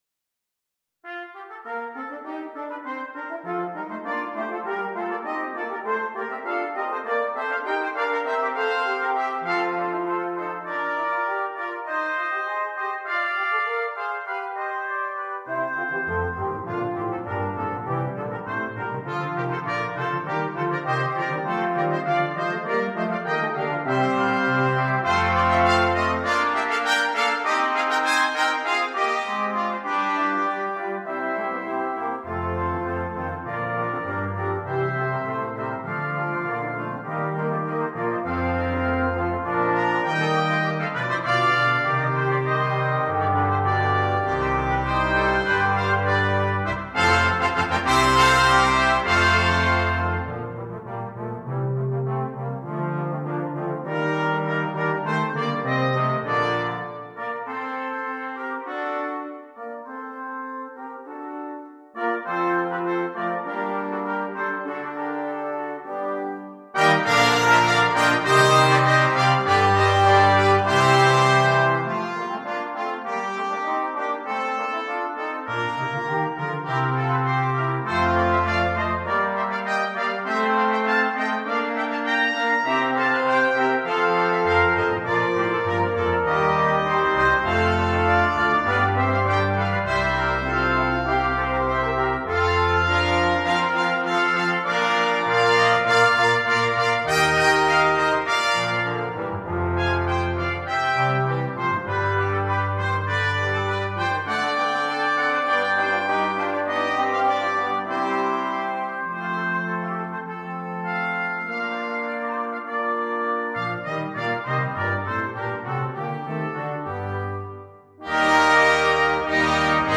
2. Blechbläserensemble
10 Blechbläser
Klassik
Stimme 4: Flügelhorn
Stimme 10: Tuba – Bass-Schlüssel